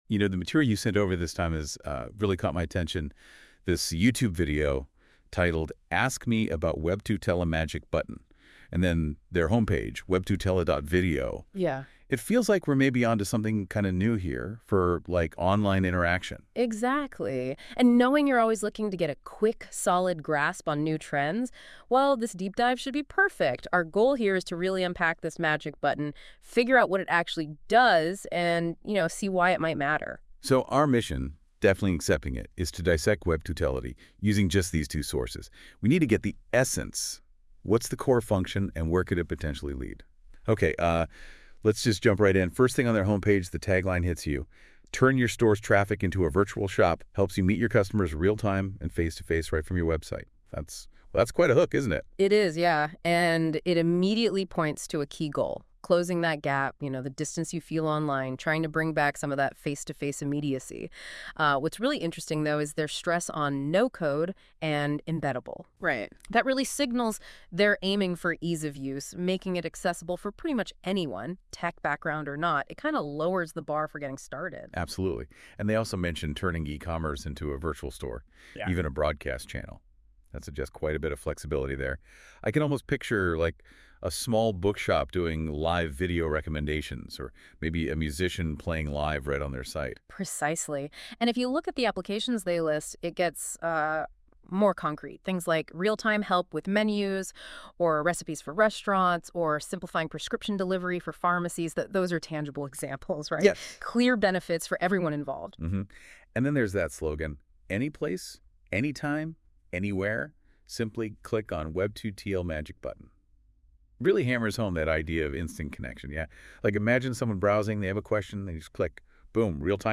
AI Podcast